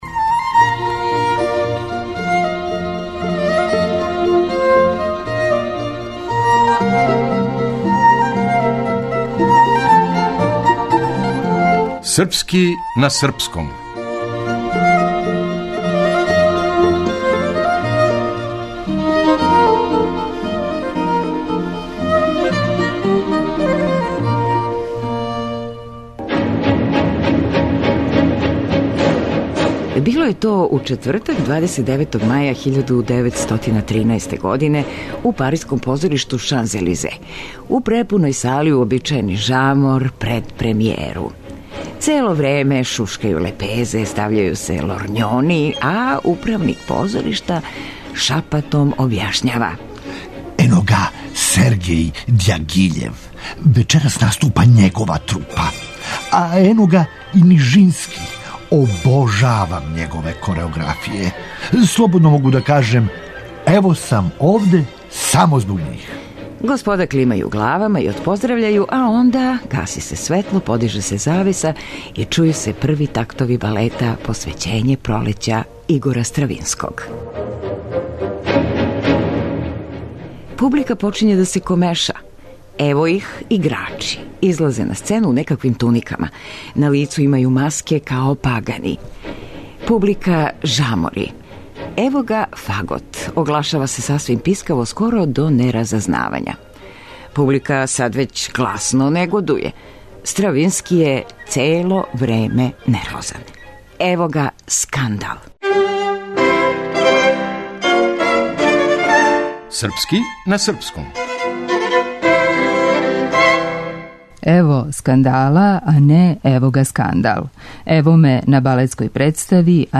Драмски уметници